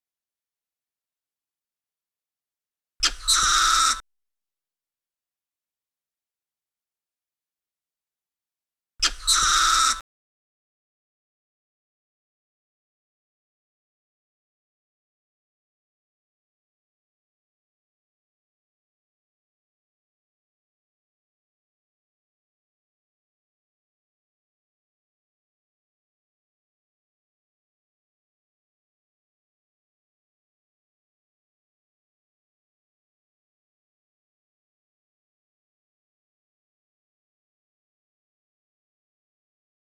Audio file of one loud call of Eulemur rufifrons. (WAV 3750 kb)